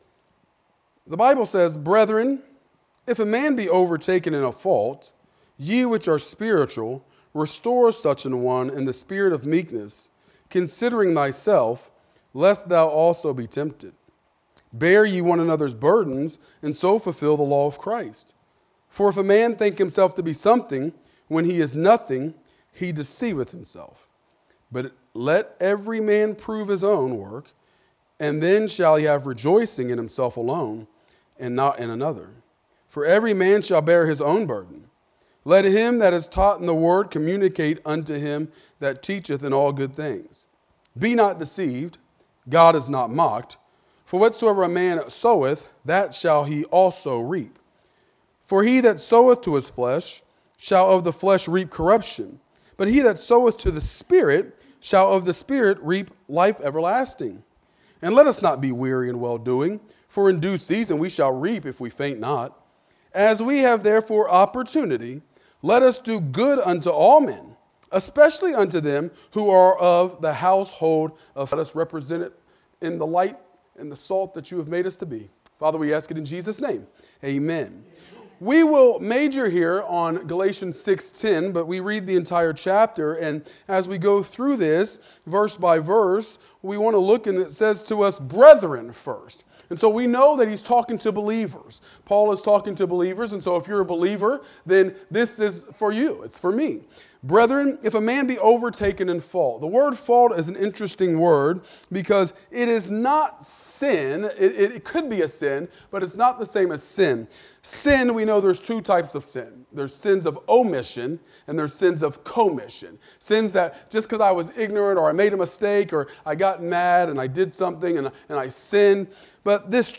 Wednesday Bible Study